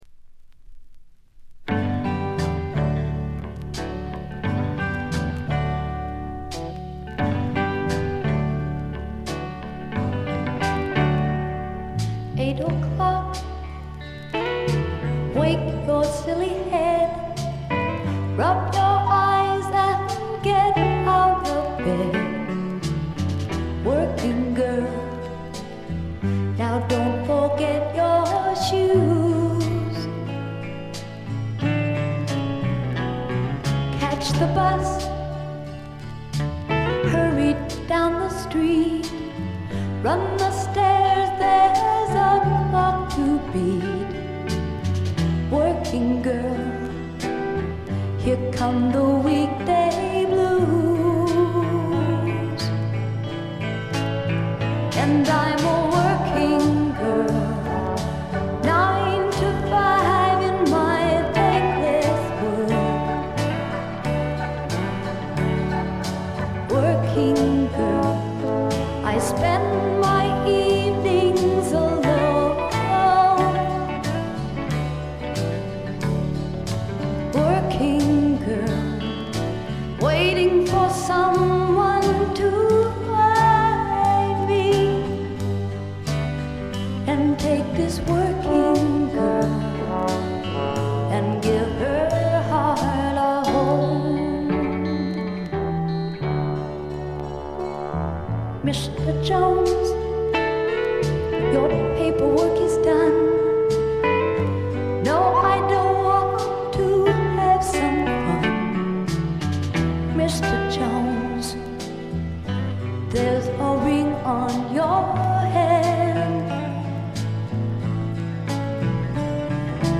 部分試聴ですが、わずかなノイズ感のみ。
試聴曲は現品からの取り込み音源です。